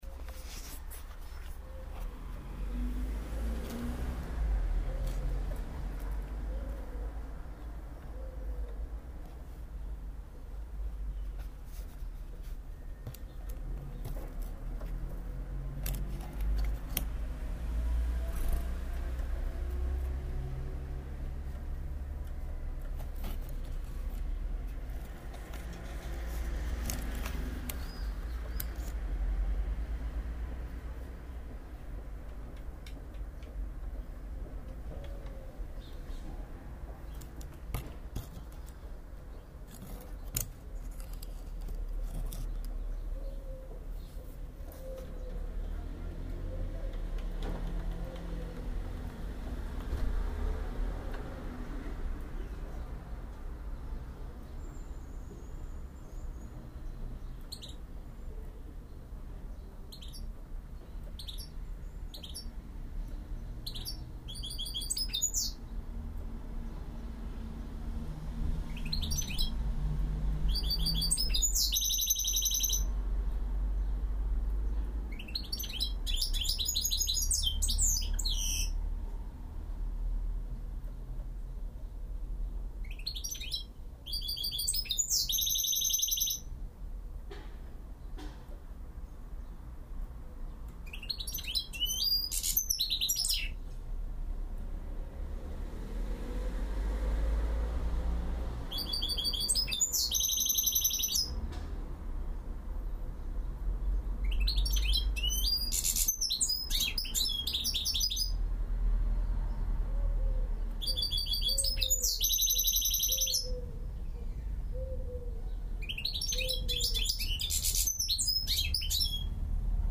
３月２４日（火） ヒルゲーロ
これは、“ヒルゲーロ”と“カナリア”のハイブリッドだそうです。
「ﾋﾟﾘﾘ、、ﾋﾟﾘﾘ、、、、、、、、、、、ﾋﾟﾋﾟﾁ、ﾋﾟﾁｮ､ﾋﾟﾁｮ、ﾋﾟｰﾋﾟｰﾋﾟｰ、ﾋﾟﾖﾋﾟﾖ、
ｷﾞｰｷﾞｰ、ｼﾞｰｼﾞｰ、ﾋﾟﾁｮ､ﾋﾟﾁｮ、ﾋﾟﾘﾋﾟﾘ、ﾁｰﾁｰﾁｰ、ﾋﾟﾛﾋﾟﾛ、ｷﾞｷﾞ、ﾋﾟーｯ
鳴くパターンは大体同じですが、微妙に少しずつ違います。
Jilguero
小さい体ですが鳴き声は大きく、
明るいうちは１日中、可愛い声で鳴き続ける愛らしい鳥です。
Jirguero.MP3